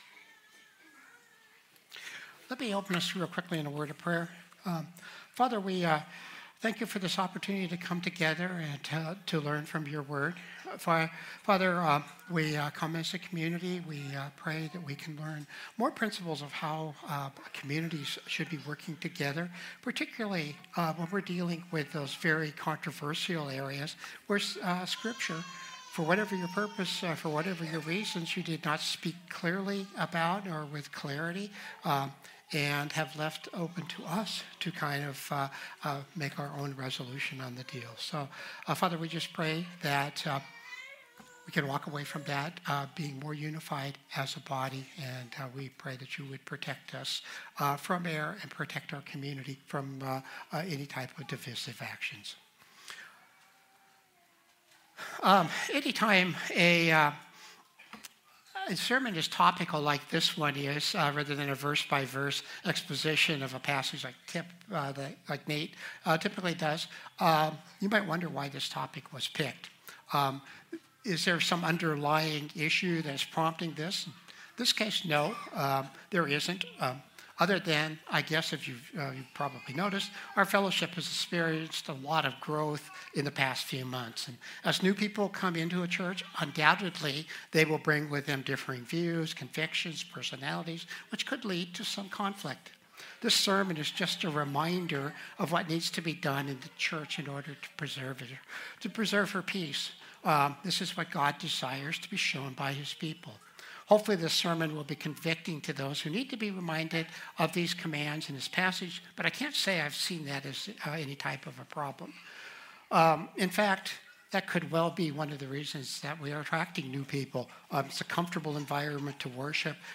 Christ the Redeemer Church | Sermon Categories Bible Books